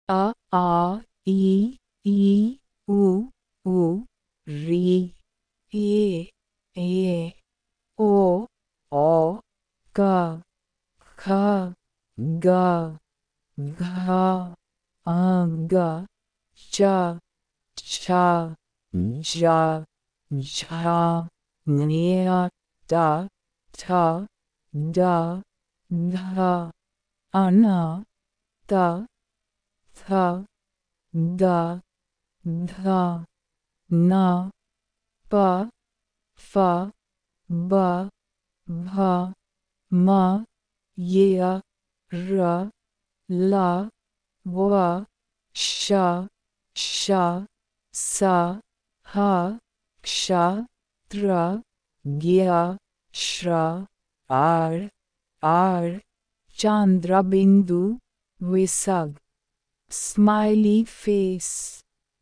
Hindi Letters Audio Clip
Hindi-letters-Phonics.mp3